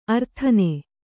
♪ arthane